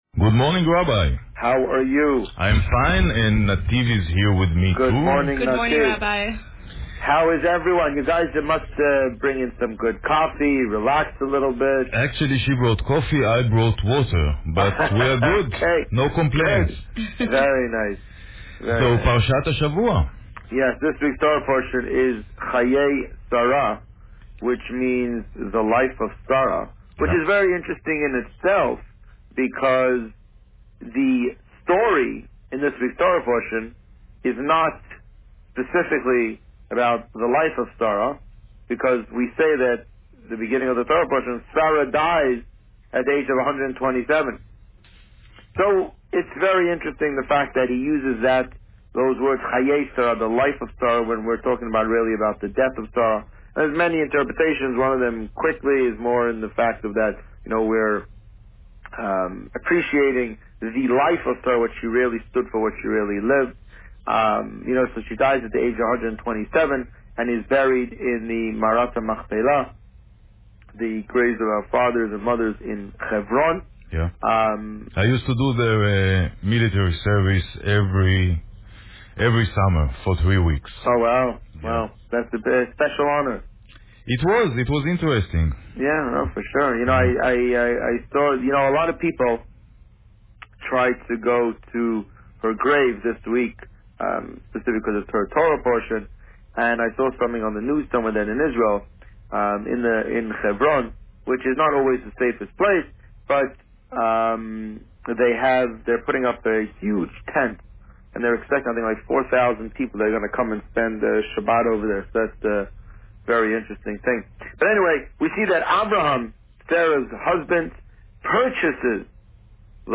This week, the Rabbi spoke about Parsha Chayei Sarah. Listen to the interview here.